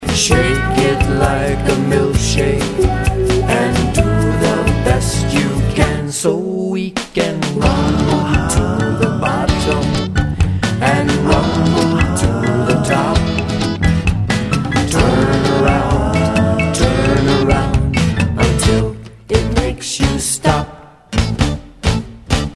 Traditional Puerto Rican